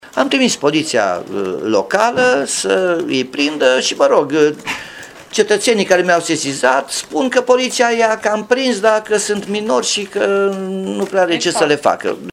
Potrivit primarului Nicolae Robu, polițiștii locali au fost la fața locului, i-au prins pe copii, dar nu au putut să le facă mare lucru: